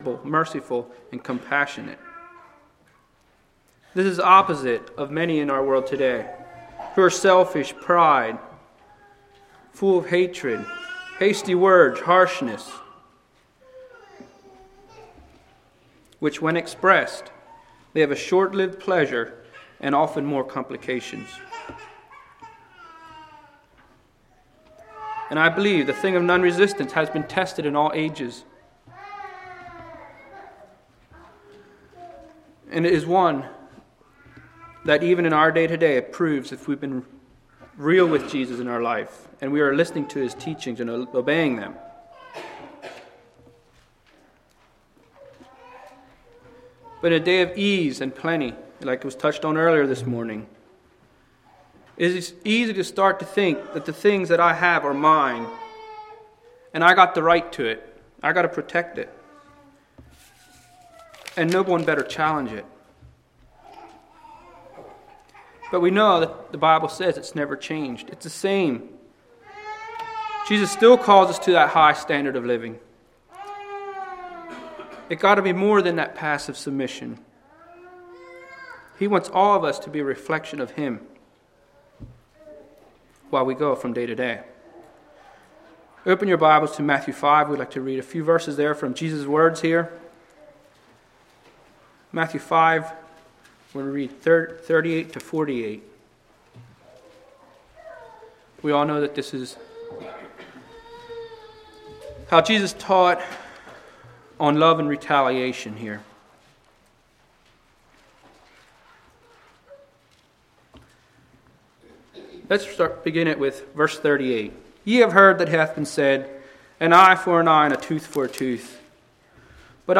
Website of Pilgrim Conference | Christian Sermons, Anabaptist e-Literature, Bible Study Booklets | Pilgrim Ministry
Play Now Download to Device Nonresistance Congregation: Blue Ridge Speaker